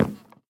Minecraft Version Minecraft Version snapshot Latest Release | Latest Snapshot snapshot / assets / minecraft / sounds / block / dried_ghast / step / wood1.ogg Compare With Compare With Latest Release | Latest Snapshot
wood1.ogg